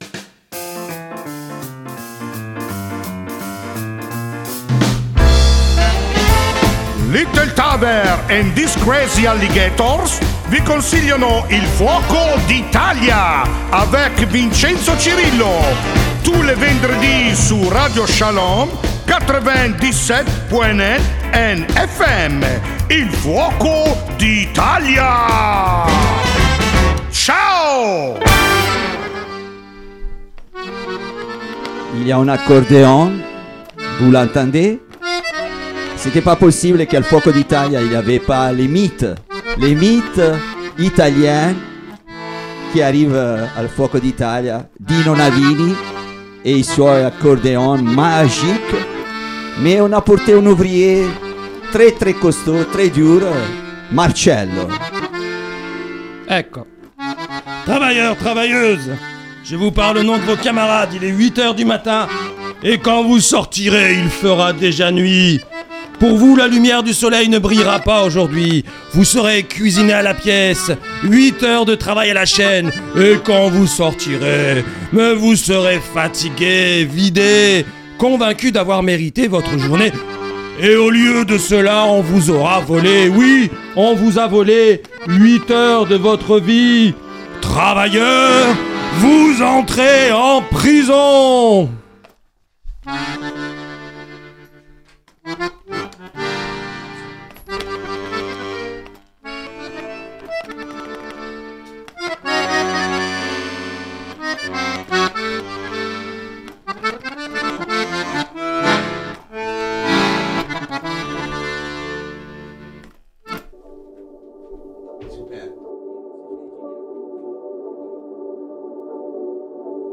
Aujourd'hui, on transforme notre salle en guinguette.